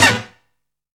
ZIP STAB.wav